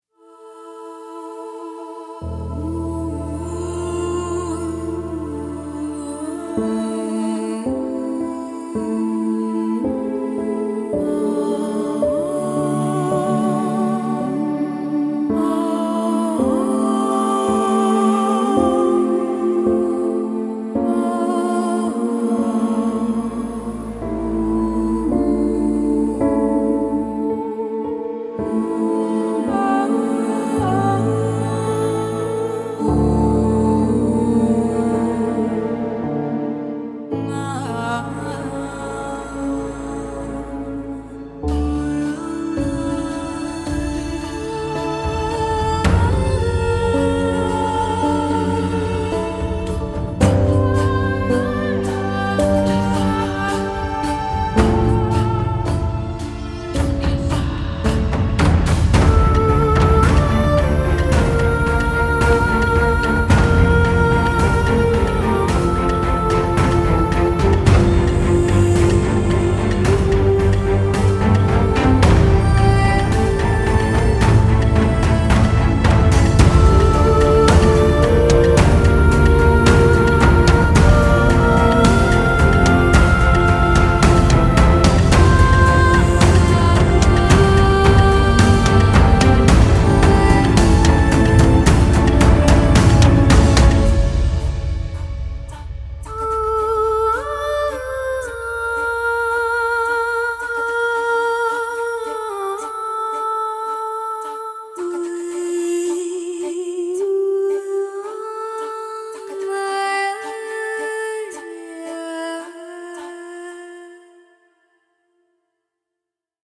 Potencia vocal intensa
Voces inmersivas y evocadoras